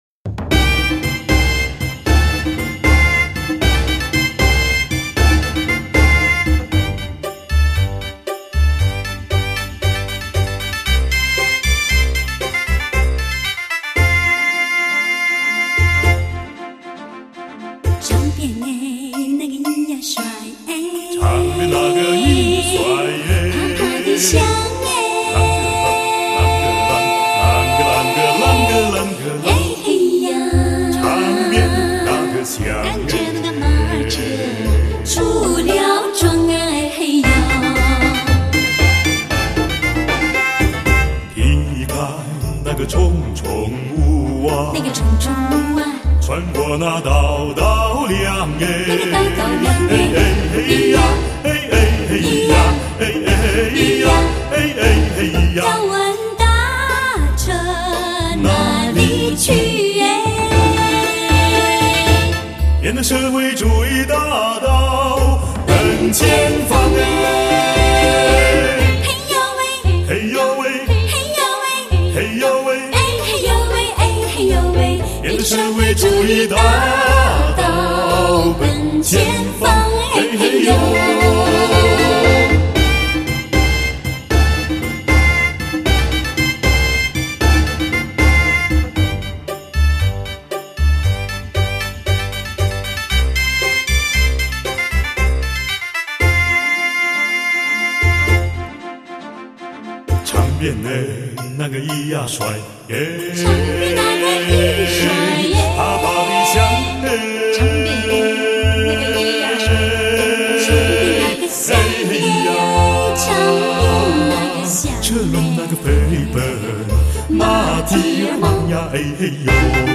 怀旧电影，犹如黑白灰的色调，随岁月沧桑变化而经典，发烧唱将，顶级天籁唱享电影力作，令经典电影更出彩。